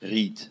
Ääntäminen
Vaihtoehtoiset kirjoitusmuodot read Synonyymit cane Ääntäminen US : IPA : [riːd] Haettu sana löytyi näillä lähdekielillä: englanti Käännös Konteksti Ääninäyte Substantiivit 1.